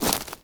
wood_tree_branch_move_12.wav